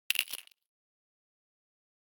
snd_hit3.ogg